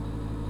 bulkhead sfx
mechanical_door_2.wav